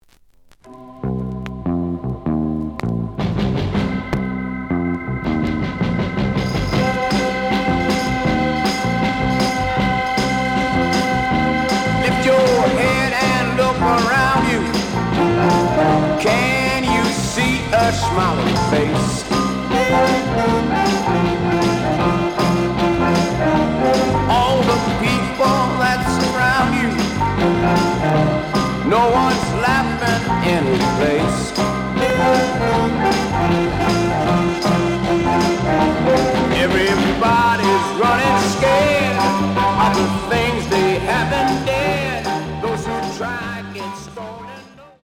The audio sample is recorded from the actual item.
●Genre: Rock / Pop
Some click noise on B side due to scratches.